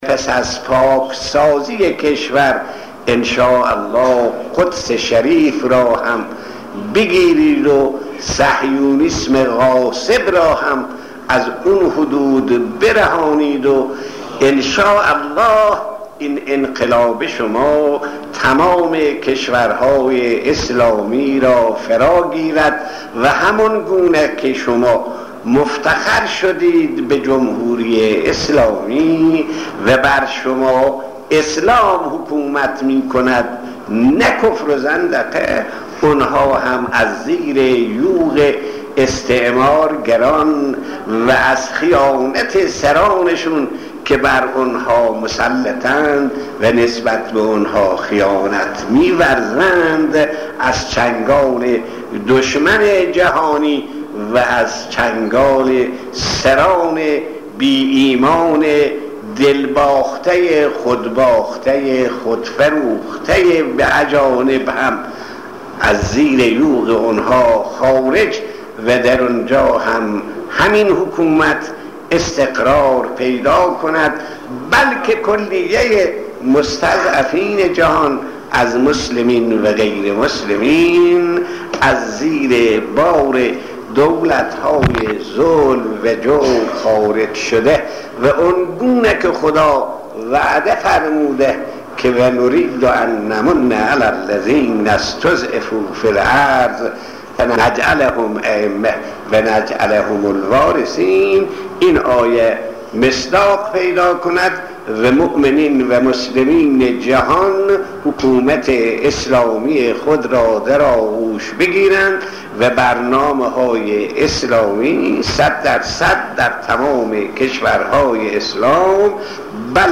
صوت/ سخناني از شهيد آيت الله صدوقى پيرامون آزادی قدس و فراگیری اسلام در دنیا